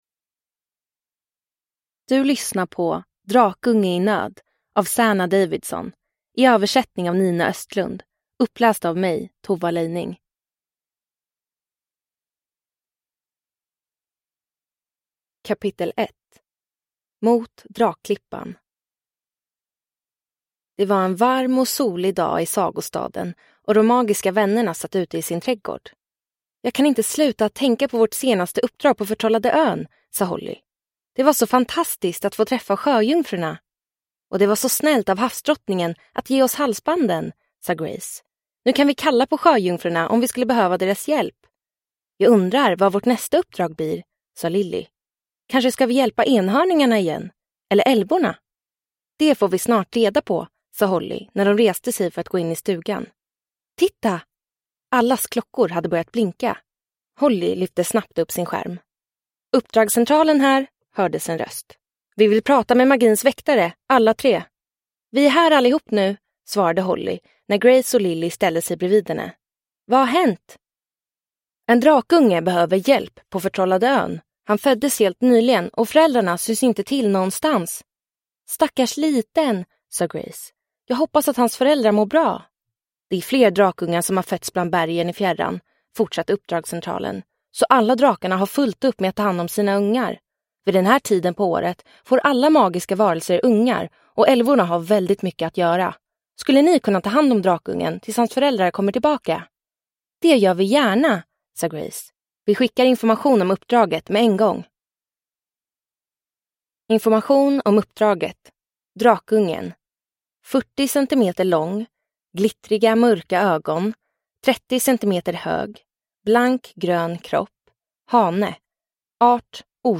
Drakunge i nöd! (ljudbok) av Zanna Davidson